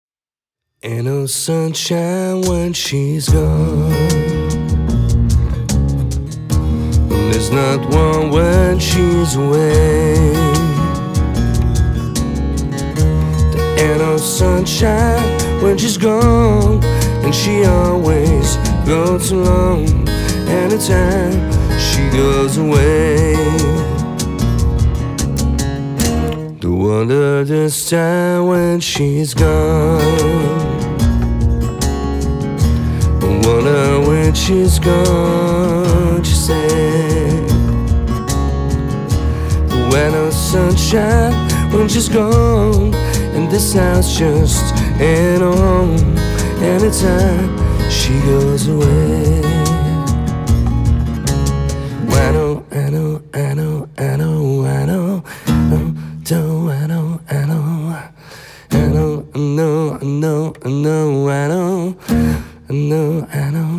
cajon & drums